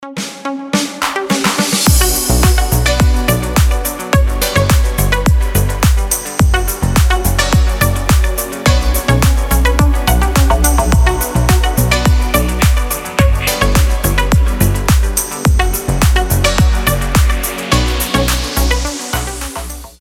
• Качество: 320, Stereo
deep house
мелодичные
без слов
ремиксы